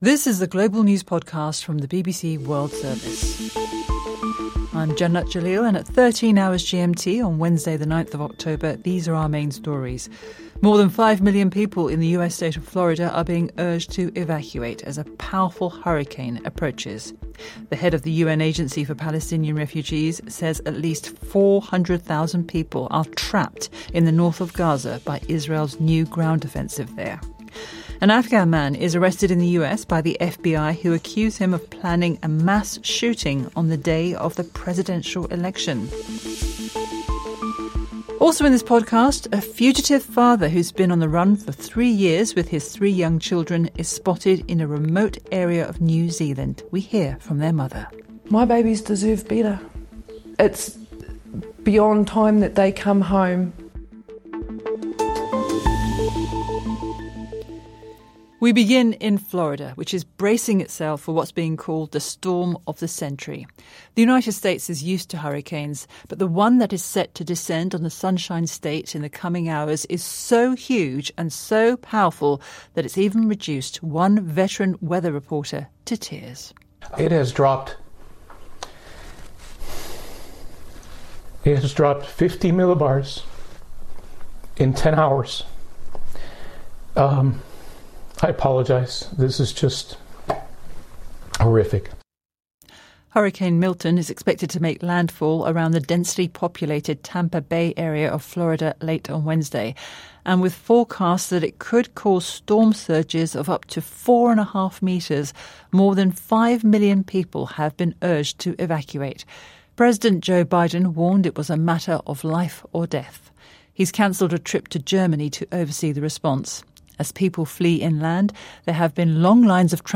BBC全球新闻